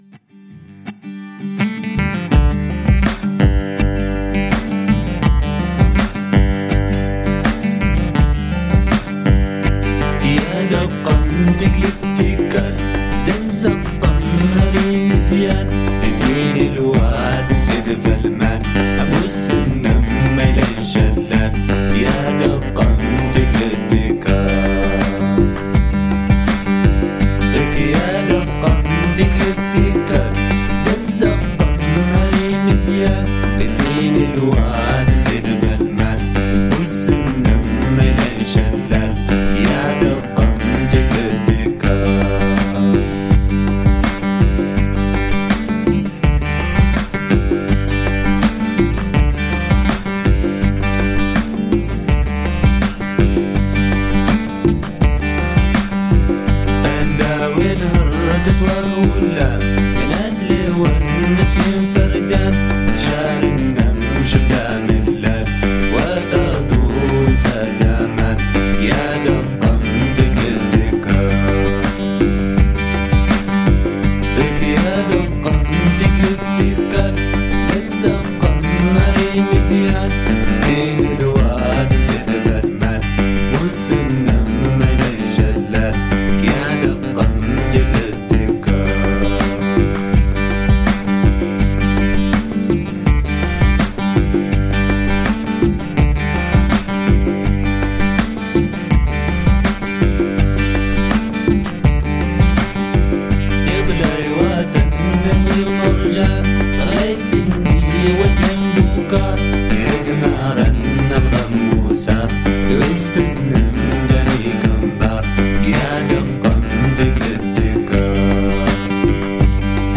African Pop Traditional